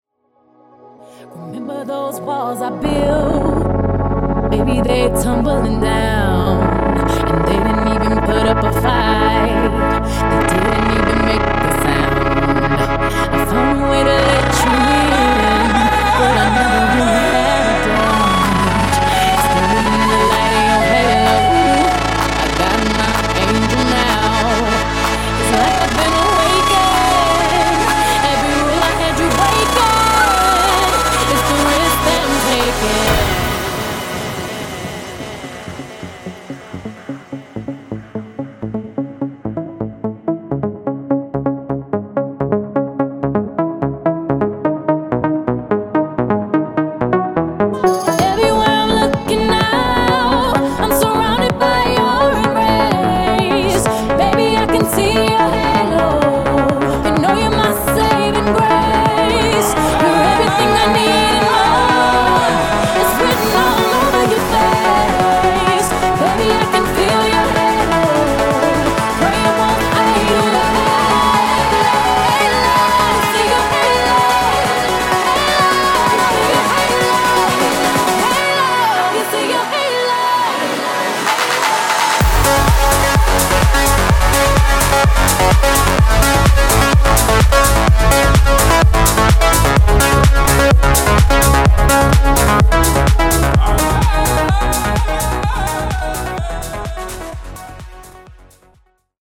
Epic Mashup)Date Added